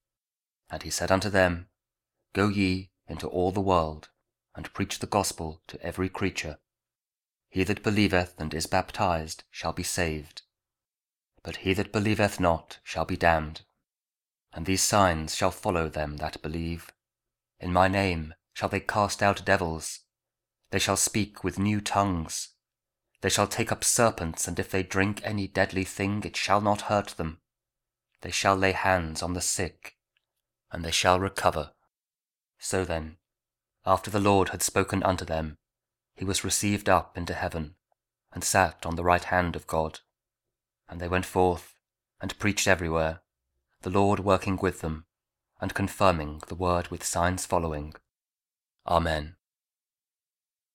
Mark 16: 15-20 | King James Audio Bible